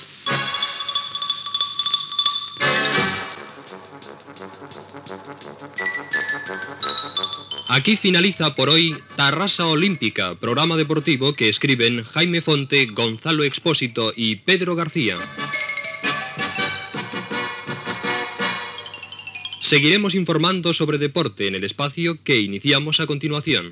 Careta de sortida del programa amb els noms dels redactors